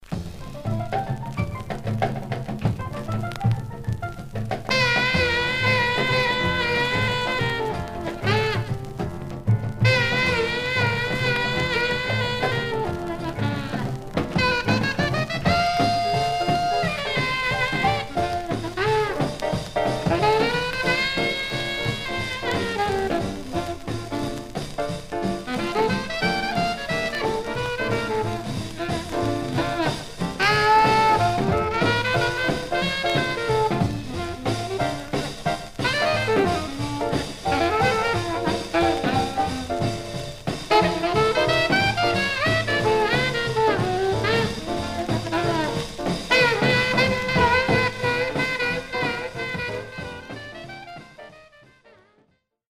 Some surface noise/wear Stereo/mono Mono
R&B Instrumental